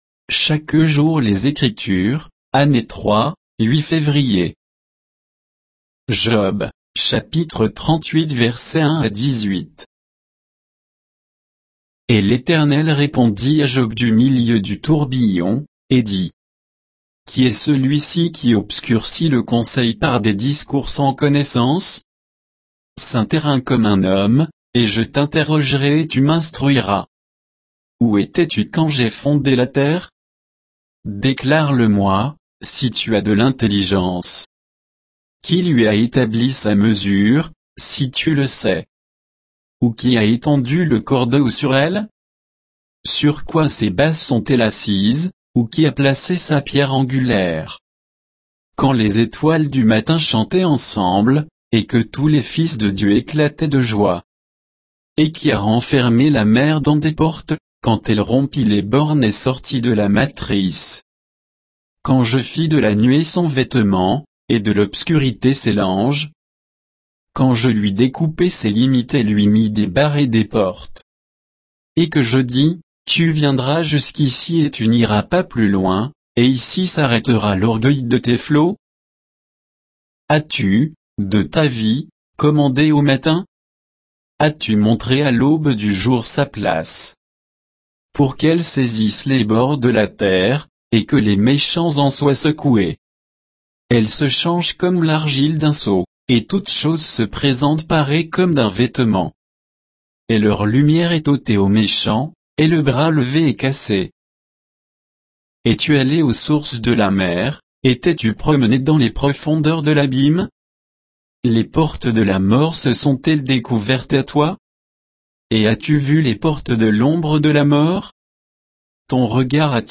Méditation quoditienne de Chaque jour les Écritures sur Job 38